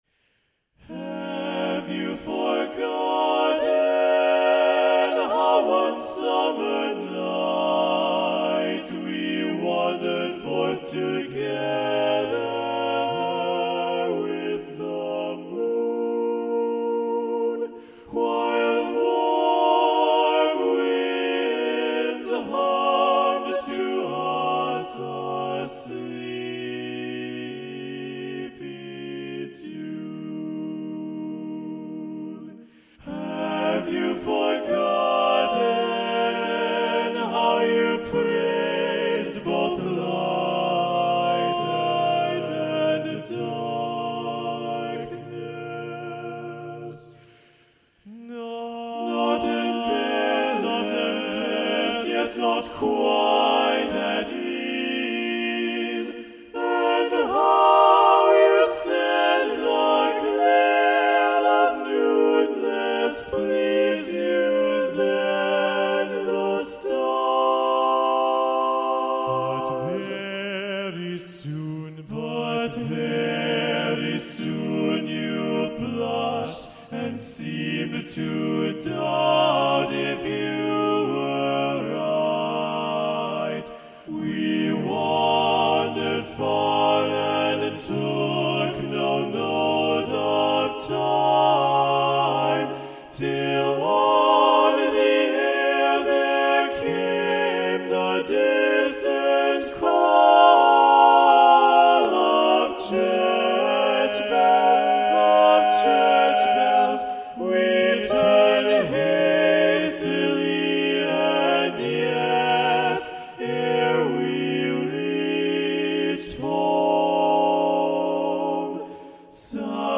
Choral ~ General ~ A Cappella
A dreamy setting